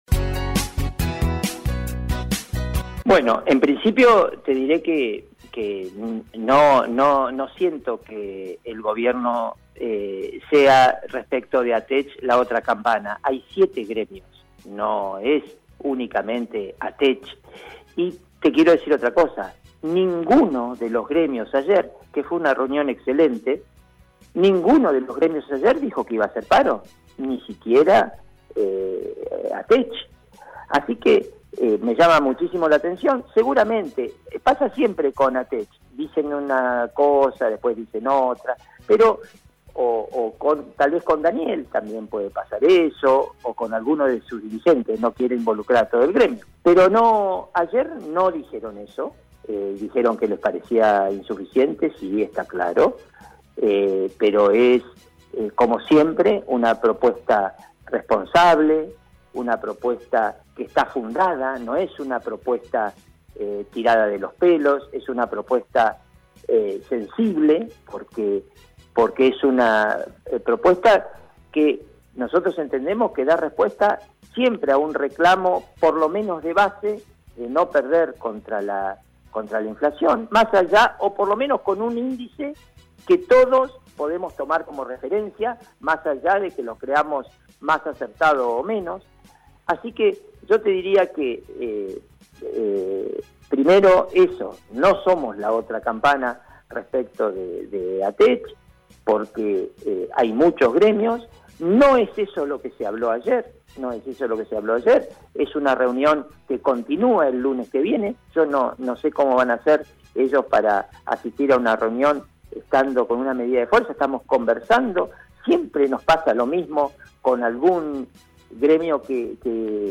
El Ministro de Educación de la provincia, José Luis Punta, en comunicación con RADIOVISIÓN, dijo que no todos los gremios de educación de la provincia están de acuerdo con un paro para el próximo lunes: